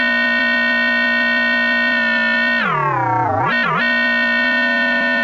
19 Harsh Realm Guitar Tone Long.wav